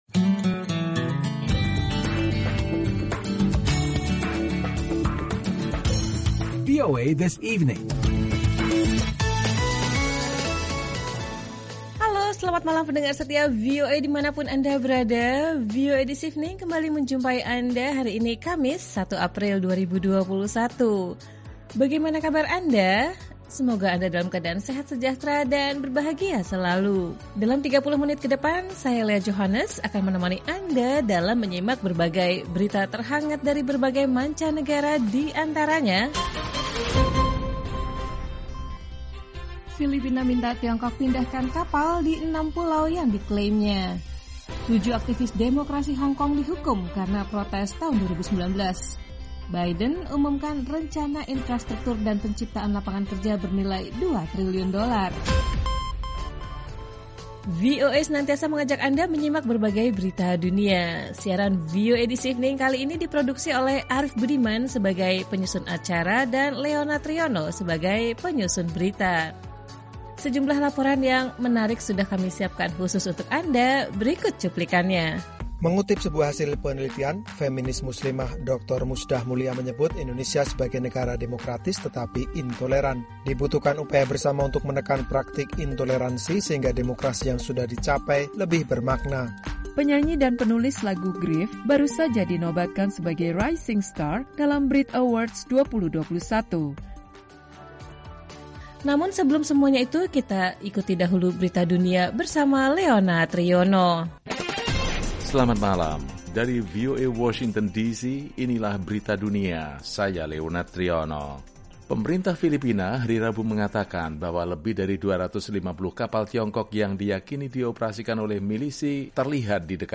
Siaran VOA This Evening